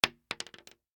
pistol_wood_4.ogg